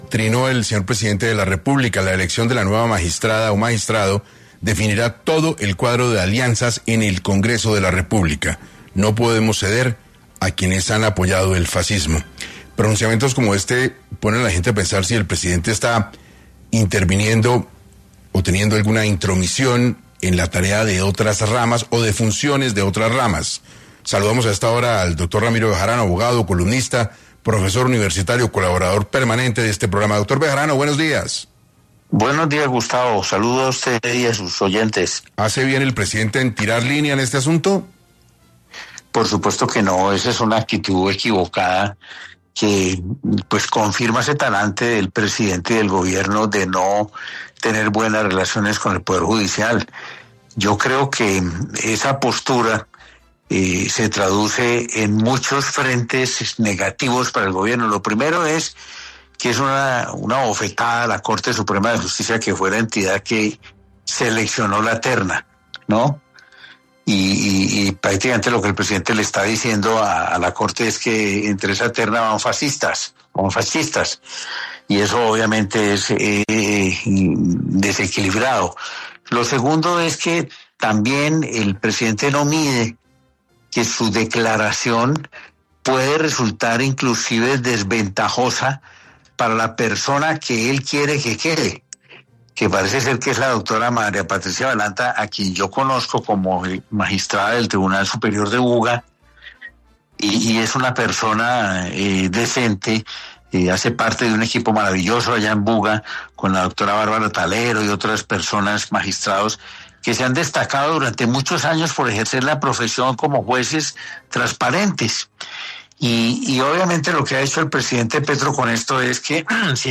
El abogado, columnista, profesor universitario, pasó por los micrófonos de 6AM, para hablar de la elección del magistrado de la Corte Constitucional.